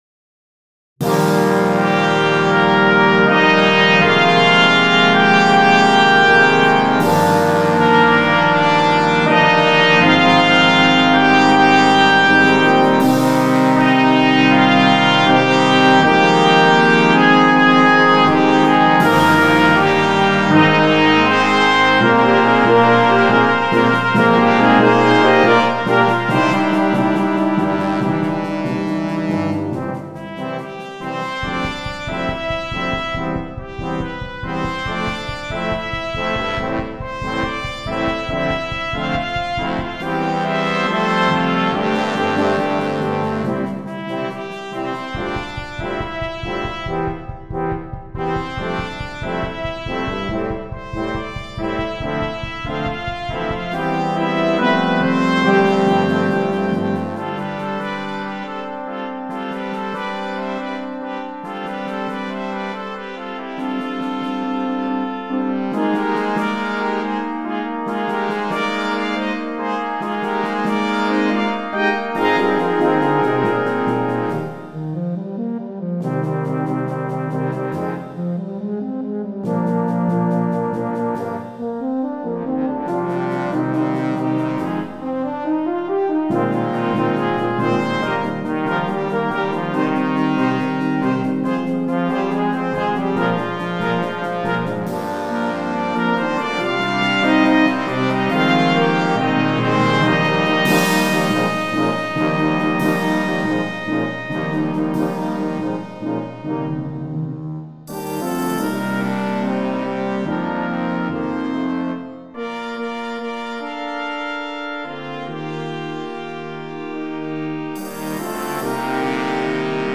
2. Brass Band
sans instrument solo
facile